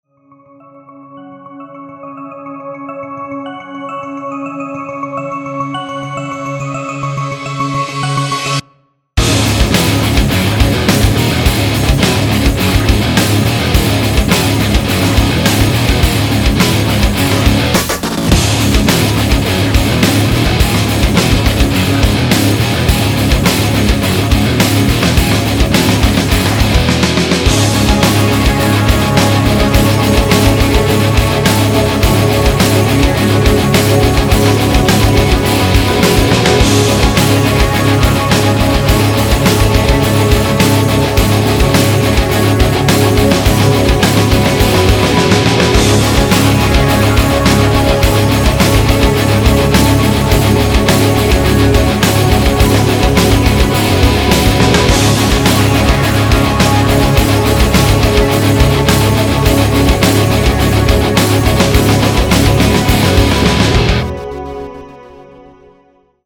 Работаю над проектом с друганом, делаем игру. В общем в сценах с битвами будет играть на фоне что-то тяжелое и атмосферное.